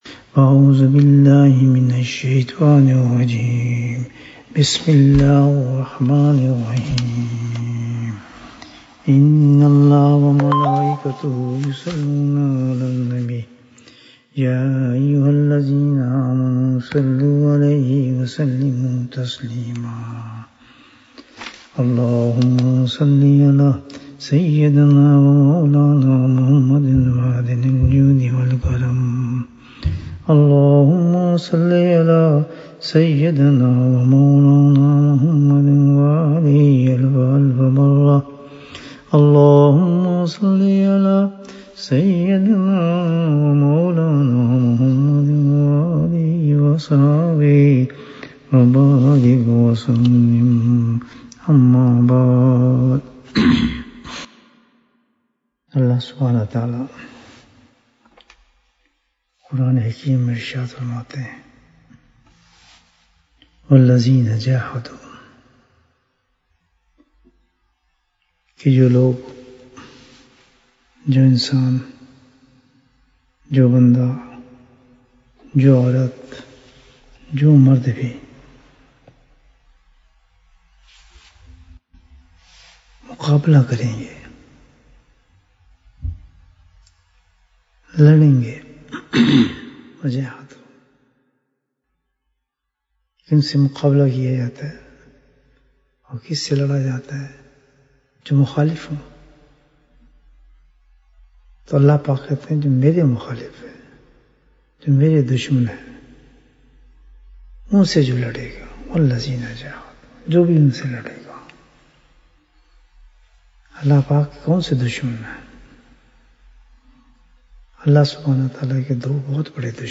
دو جنتوں کا انعام Bayan, 35 minutes22nd October, 2020